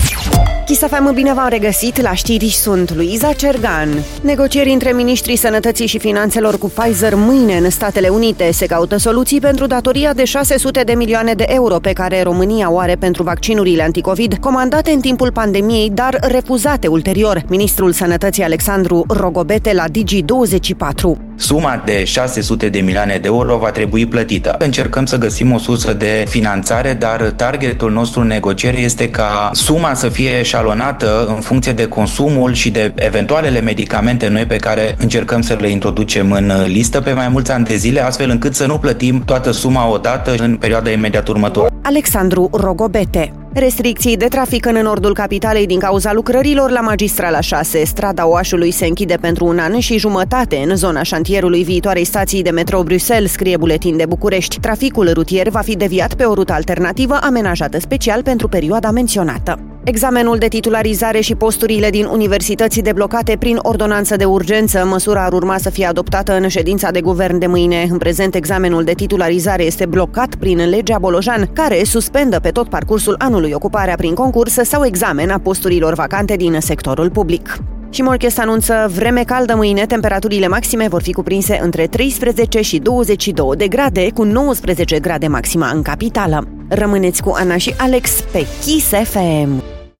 Știrile zilei de la Kiss FM - Știrile zilei de la Kiss FM